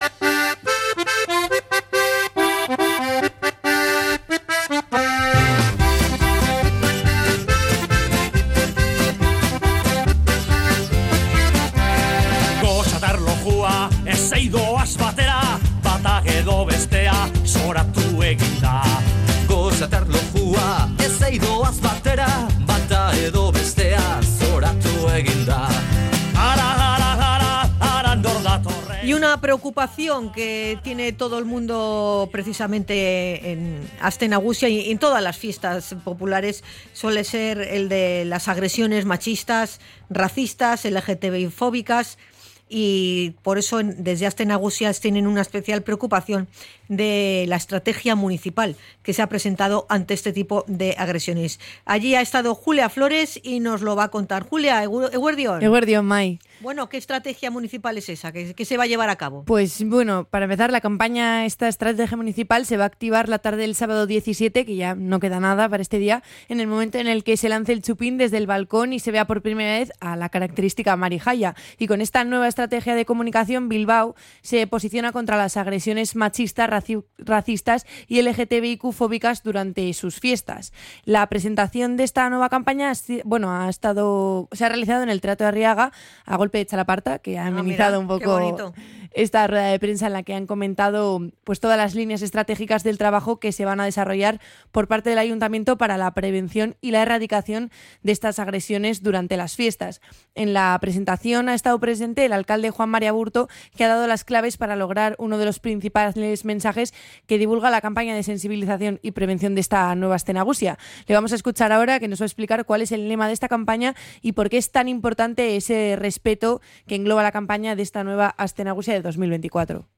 En una rueda de prensa en el Teatro Arriaga, el alcalde de la Villa, Juan Mari Aburto, ha manifestado que «el respeto es el principio de todo: una relación, un rollo, un noviazgo, también entre los comparseros» y ha defendido las «actitudes nobles en relaciones y ligues».
CRONICA-ATAQUES-MACHISTAS-ASTE-NAGUSIA.mp3